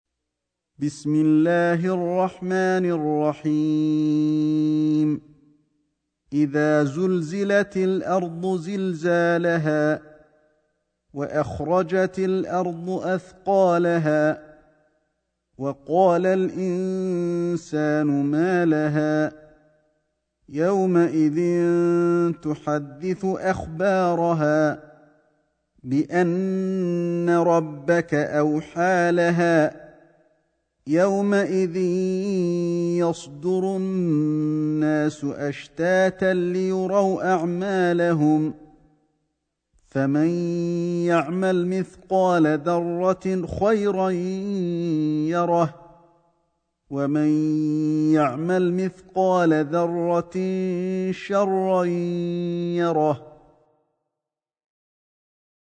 سورة الزلزلة > مصحف الشيخ علي الحذيفي ( رواية شعبة عن عاصم ) > المصحف - تلاوات الحرمين